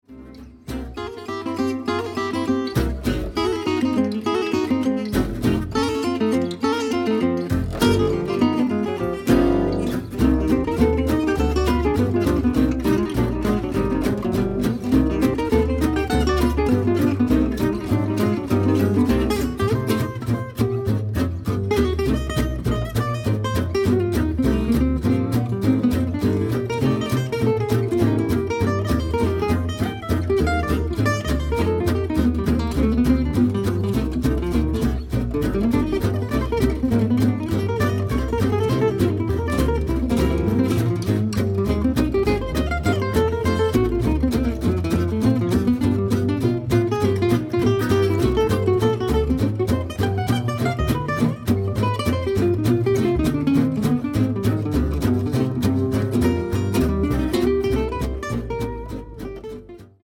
one of the most estimated jazz saxophone player.
double-bass